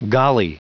Prononciation du mot golly en anglais (fichier audio)
Prononciation du mot : golly